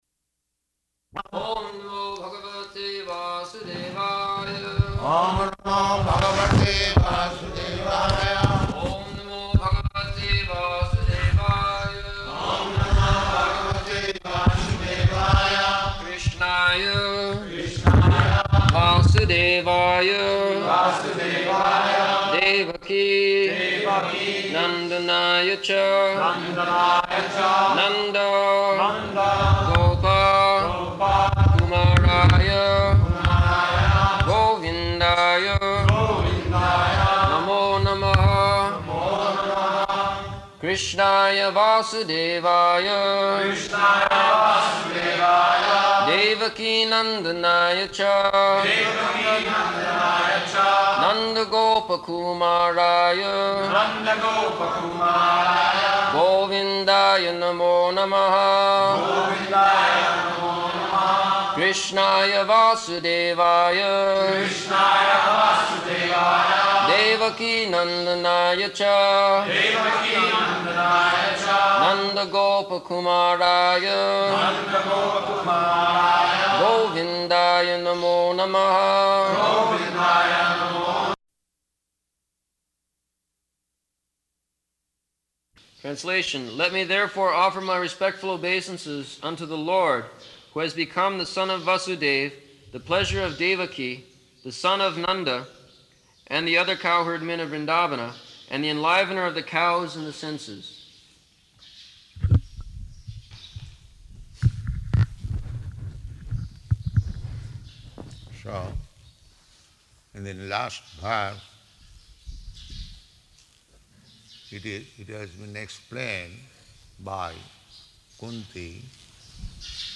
October 1st 1974 Location: Māyāpur Audio file
[Prabhupāda and devotees repeat] [leads chanting of verse, etc.]